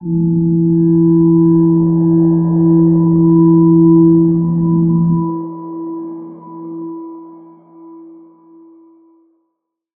G_Crystal-E4-f.wav